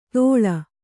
♪ tōḷa